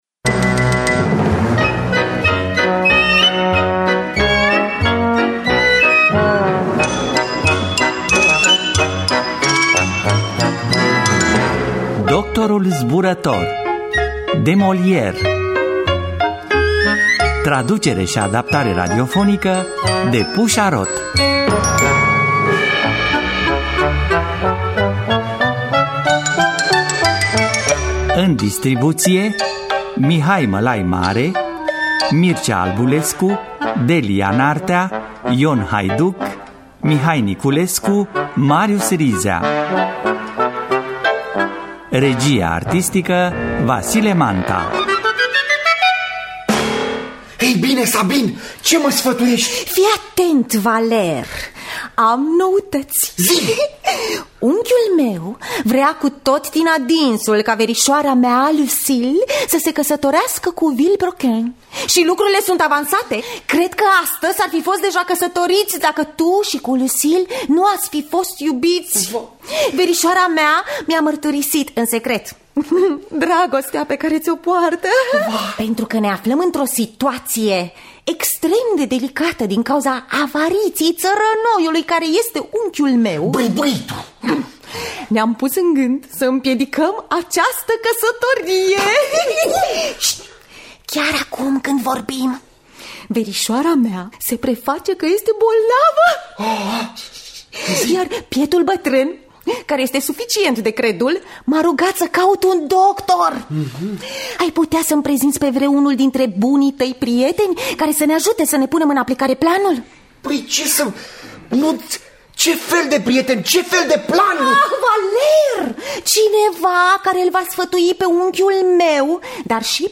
Doctorul Zburător de Molière – Teatru Radiofonic Online